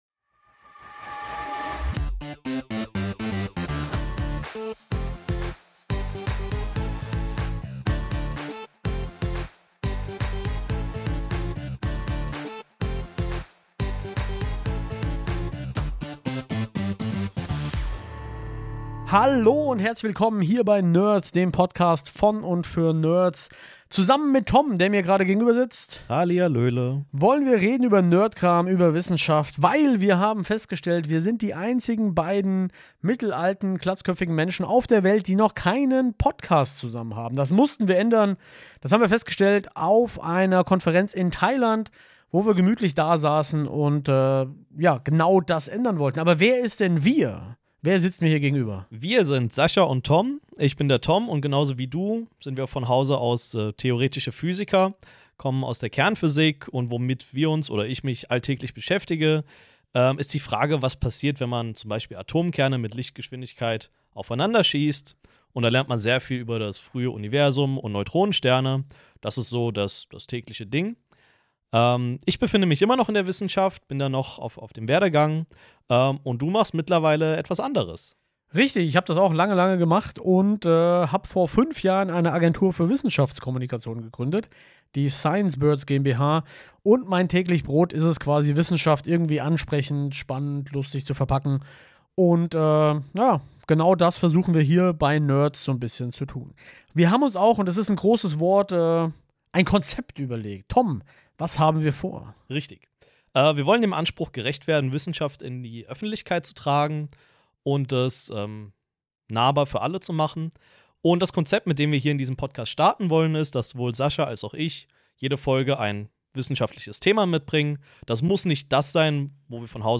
Aber Achtung, es sind zwei theoretische Physiker, die zu viel